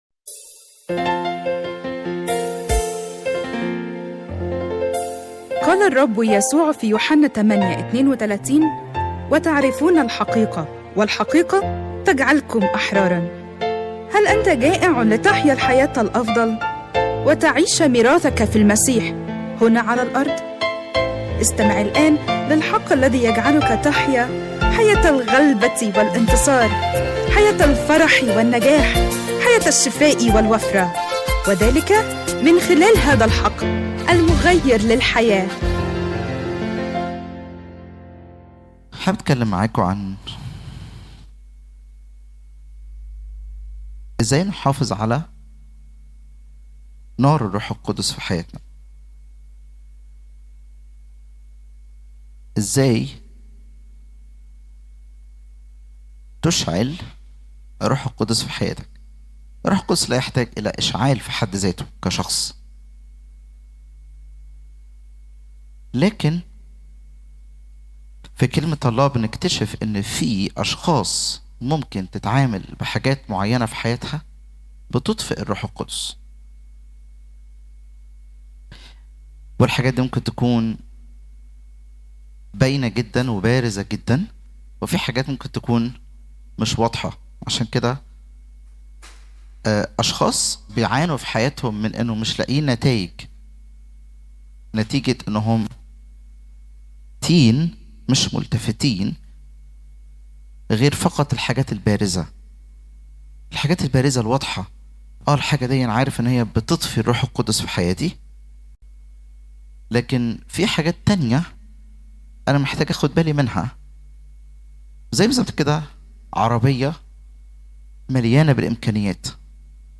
لسماع العظة على الساوند كلاود اضغط هنا العظة مكتوبة كيف نحافظ على نار الروح القدس في حياتنا؟